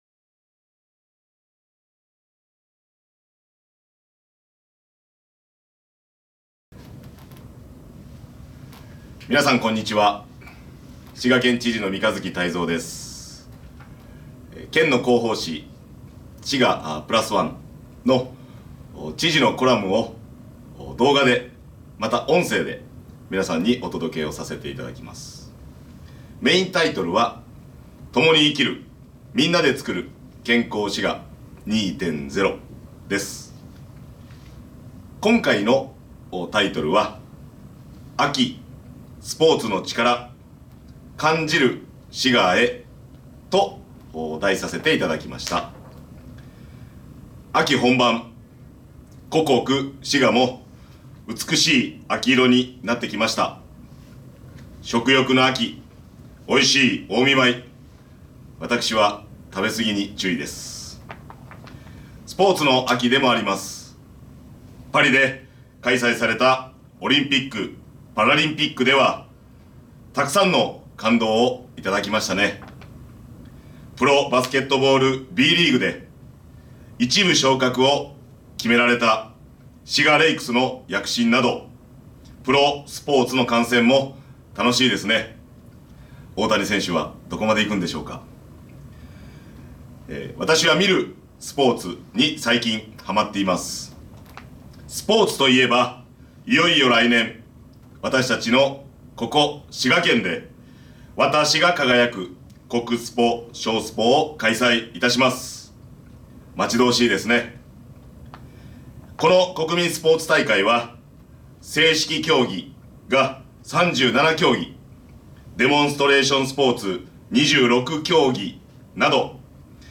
暮らしの情報局 おしらせ くらしの情報局vol209 (PDF:7 MB) コラム みんなでプラスワン！ vol.49 【音声版】今月のみんなでプラスワン（知事朗読） Vol.49 (mp3:8 MB) ほっとサロン 「滋賀プラスワン」秋号へのご感想や県政へのご意見などをお寄せいただいた方の中から抽選でプレゼントが当たります！